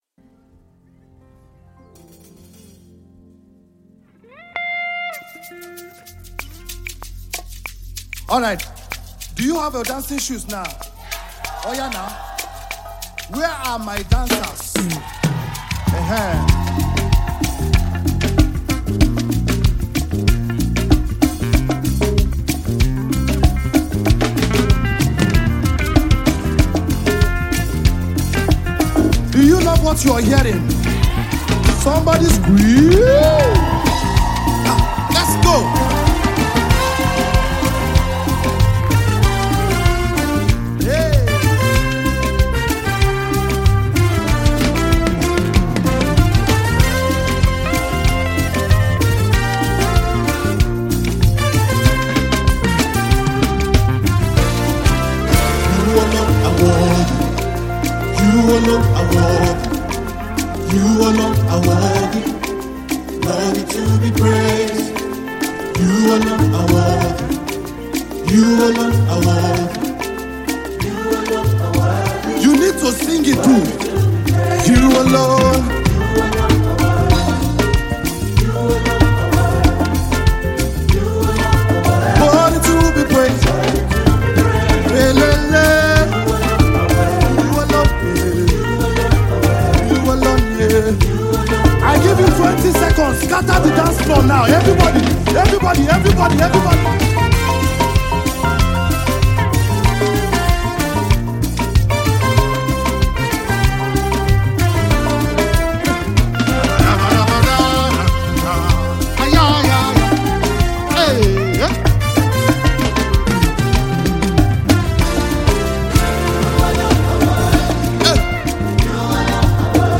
Exceptional Nigerian gospel singer and performer